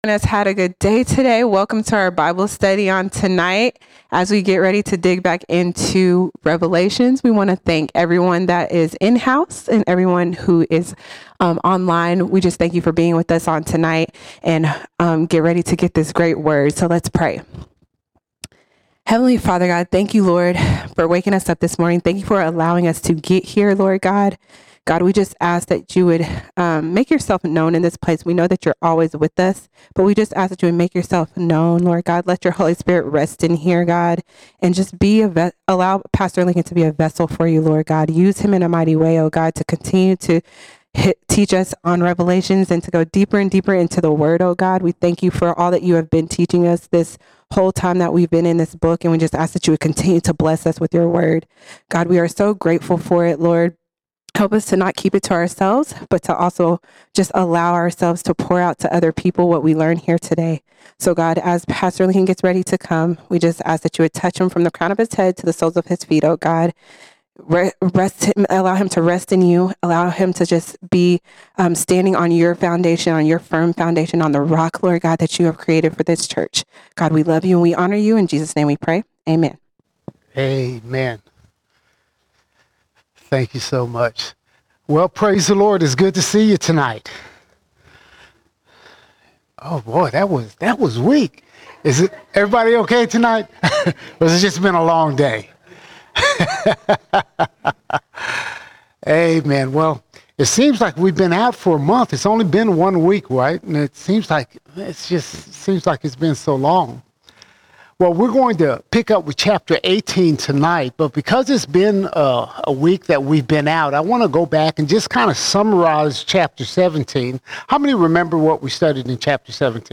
5 December 2024 Series: Revelation All Sermons Revelation 18:1 to 18:24 Revelation 18:1 to 18:24 We examine the fall of Babylon.